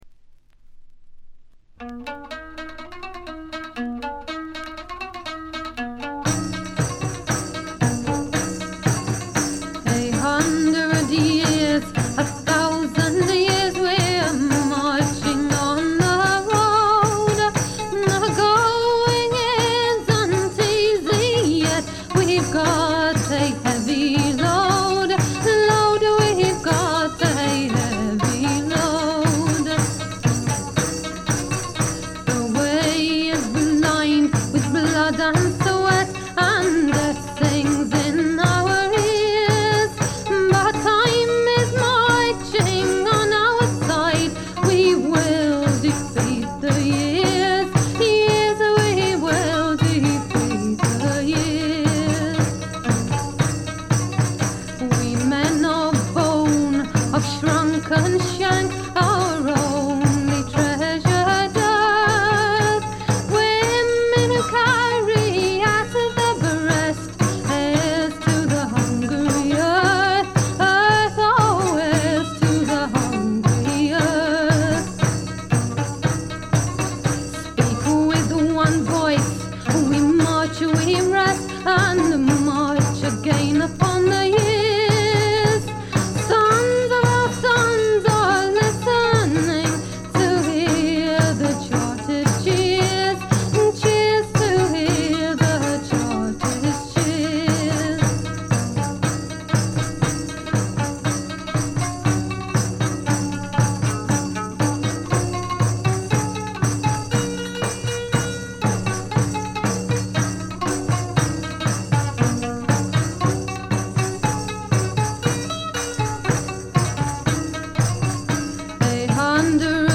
レア度、内容ともに絶品のフィメールトラッドフォークです。
試聴曲は現品からの取り込み音源です。